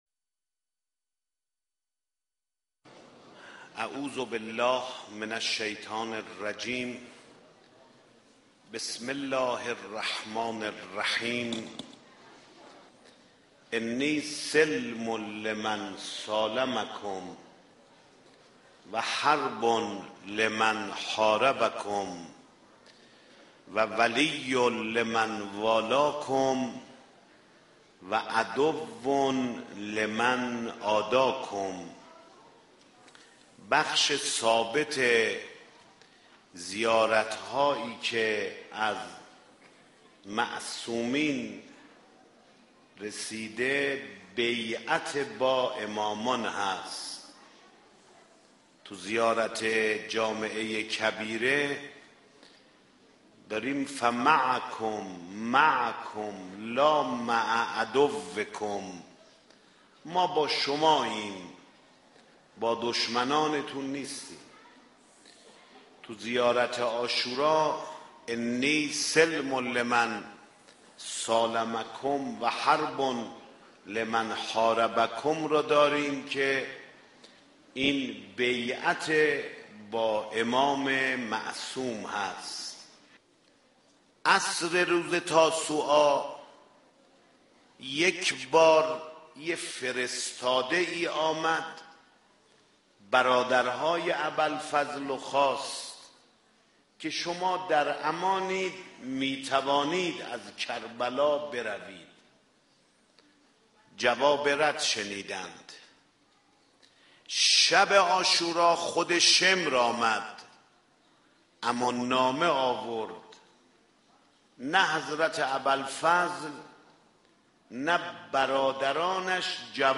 سخنرانی حضرت آیت الله خاتمی_پیرامون امامت…